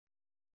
♪ gannakāṛa